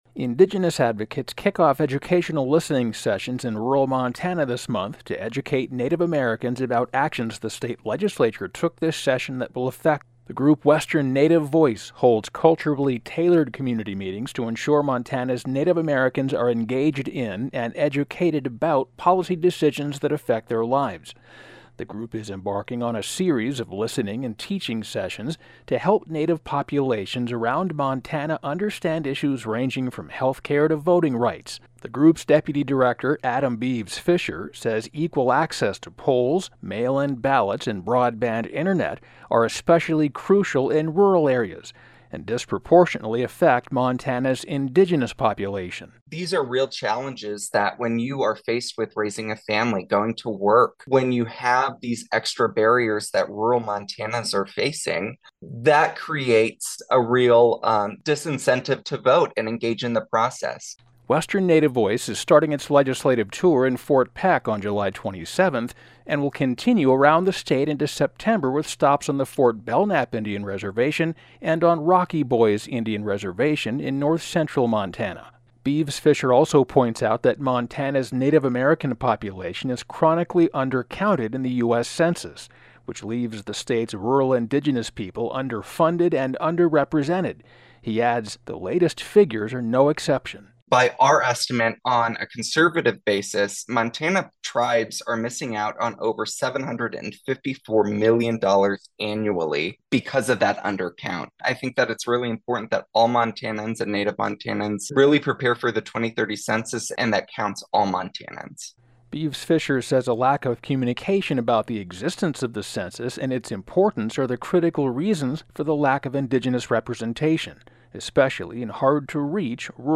Big Sky Connection - A prominent Native American advocacy group starts its educational tour around Montana this month to inform the state's Indigenous tribes about the issues affecting them from the recent legislative session.